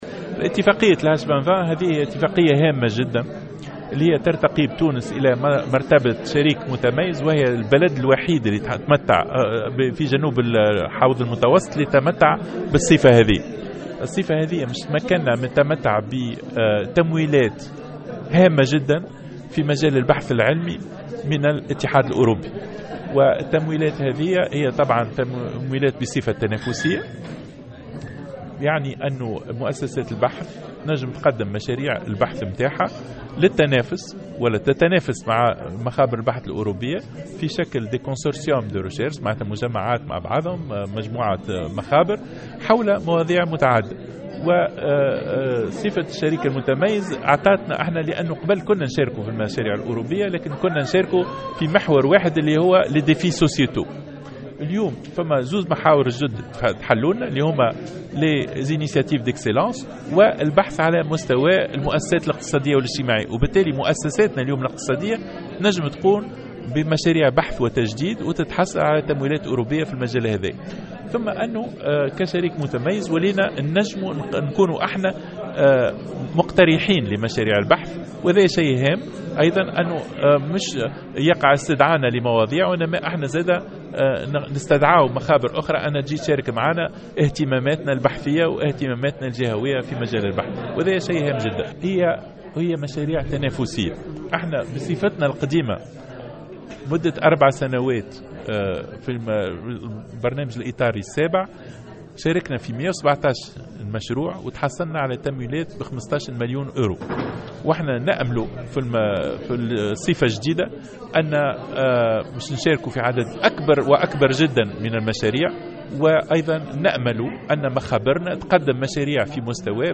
تحدث وزير التعليم العالي والبحث العلمي، شهاب بودن، في تصريح لمراسل الجوهرة أف أم اليوم الخميس، على هامش جلسة في مجلس نواب الشعب للاستماع إليه، عن اتفاقية H2020 التي تم توقيعها مع الاتحاد الاوربي وتتمتع بموجبها تونس بصفة شريك متميز كأول دولة في جنوب المتوسط تمنح هذه الصفة.